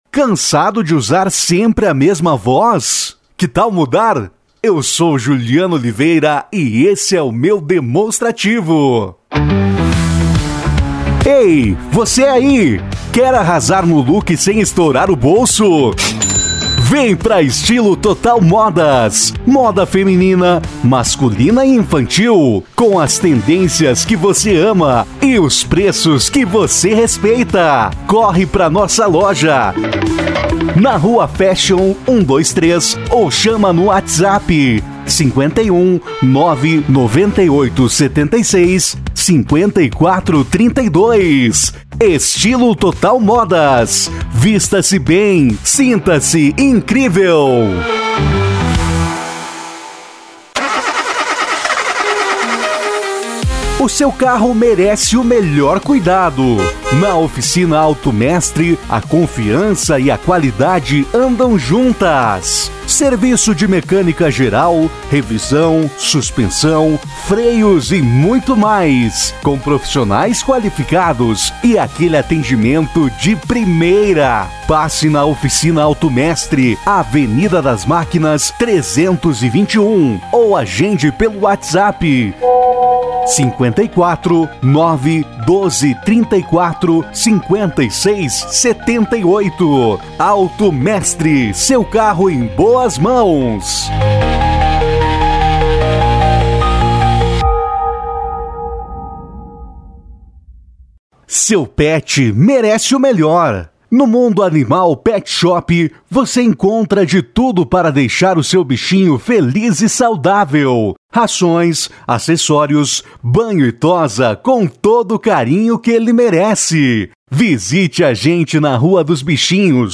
Spot Comercial
Vinhetas
VT Comercial
Padrão
NÃO TENHO NADA CONTRA AOS SUTAQUES REGIONAIS, MAS É MUITO EXTRANHA A LEITURA DESSE LOCUTOR, FICA MUITO FORA DO ESTILO UNIVERSAL DE LOCUÇÃO.